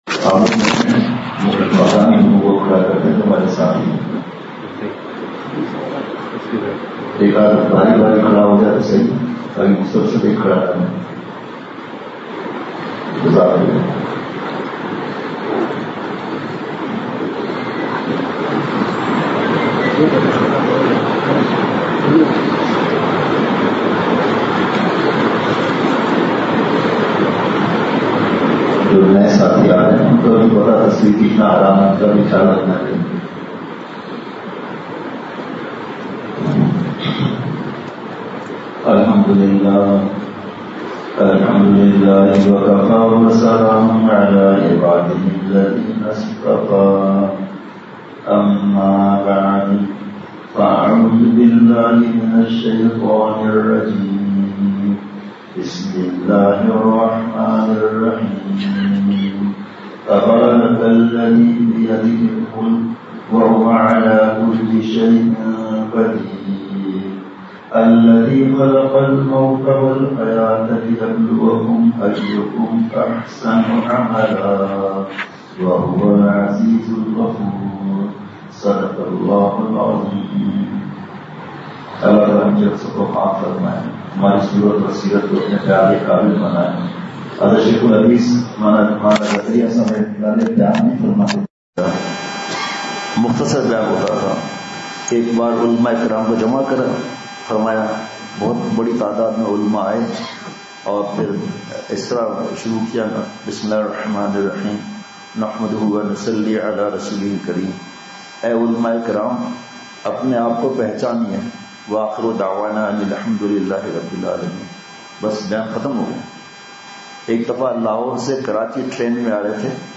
*بمقام: بعد مغرب بیان ۔ حرث العلوم باغیچہ چمن* *بعد مغرب بیان*
*انتہائی جوش میں ارشادات خود کو بدلیں پھر معاشرہ بدلے گا۔